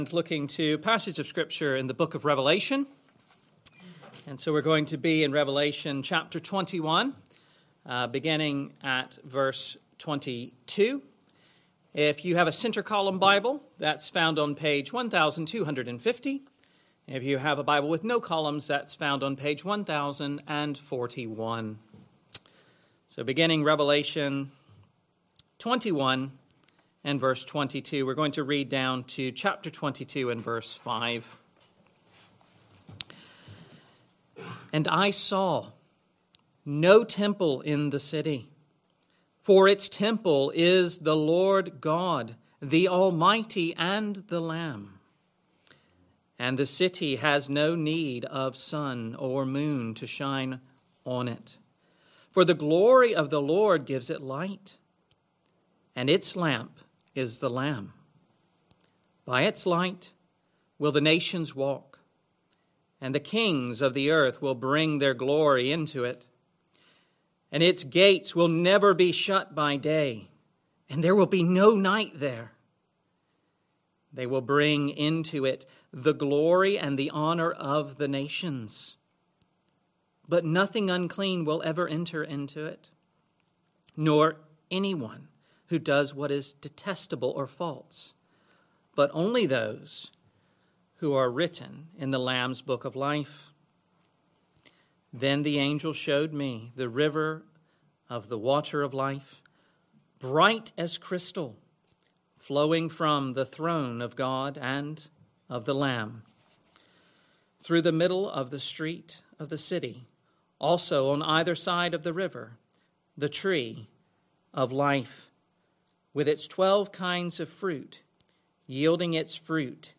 Sunday PM Service Sunday 28th December 2025 Speaker